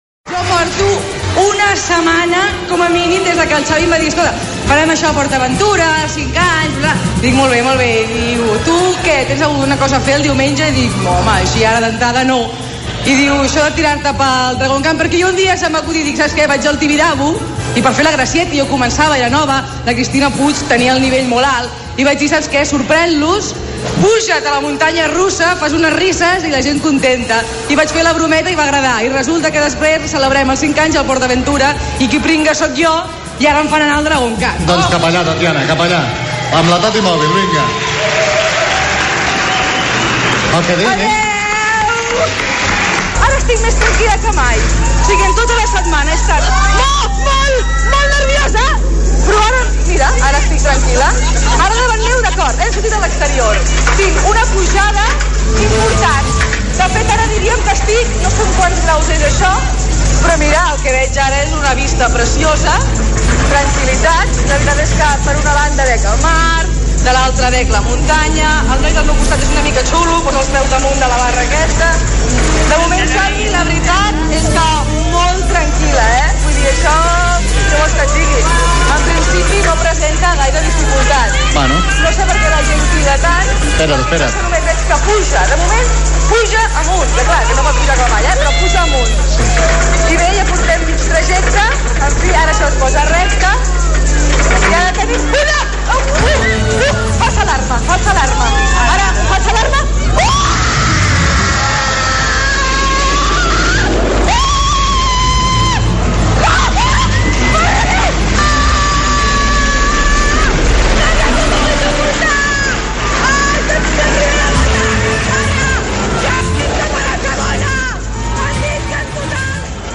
El món a RAC1: reportatge des del Dragon-khan - RAC1, 2005